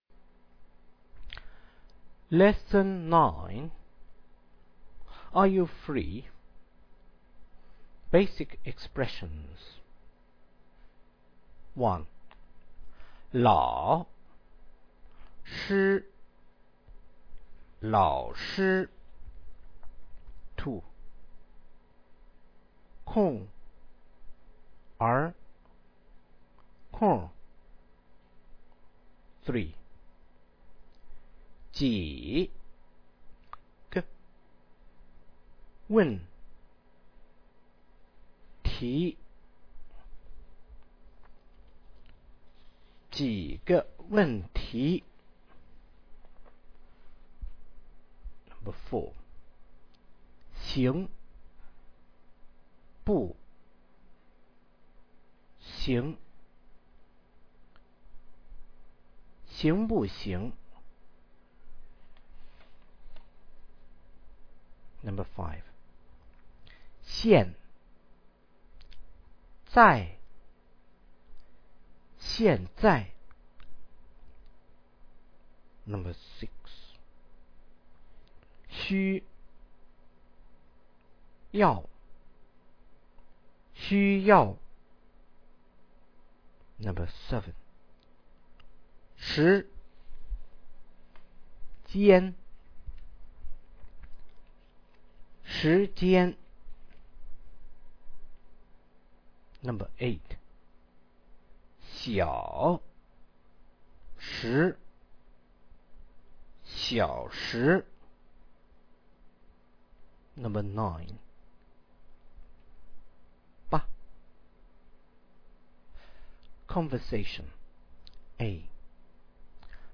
Tape version